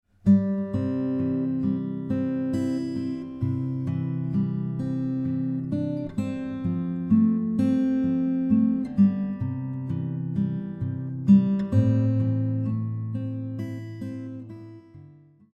Backing Track
Amazing Grace level 2 (7th chords)
Amazing Grace guitar arrangement
In this section, we are essentially connecting the melody and chords together using a finger-style method of playing.